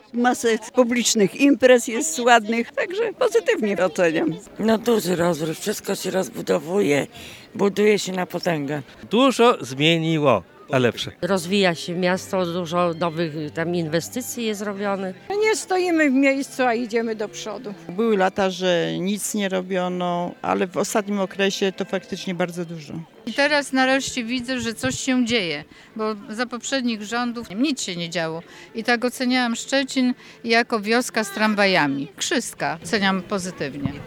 Tak w Różanym Ogrodzie, mieszkańcy świętowali urodziny Szczecina.
Mieszkańcy zgromadzeni na uroczystości urodzinowej w pozytywnych słowach oceniali rozwój miasta.